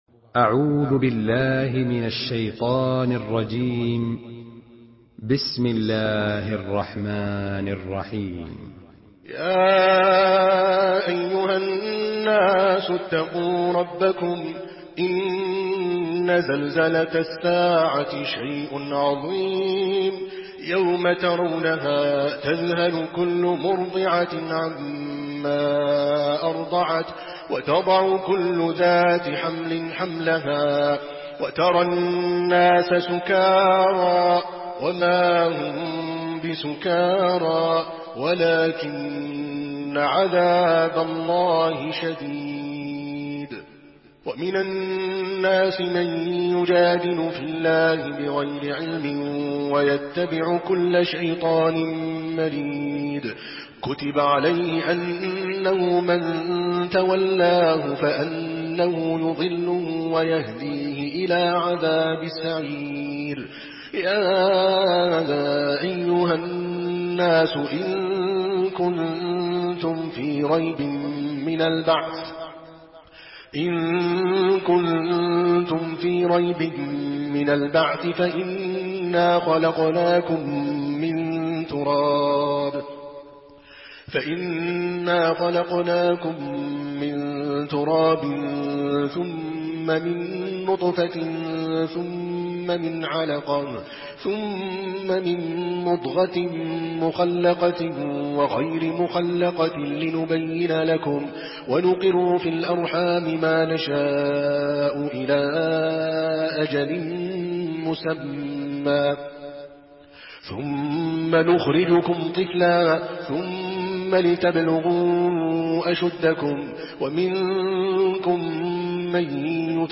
Surah Al-Haj MP3 by Adel Al Kalbani in Hafs An Asim narration.
Murattal